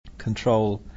Irisch-Englisch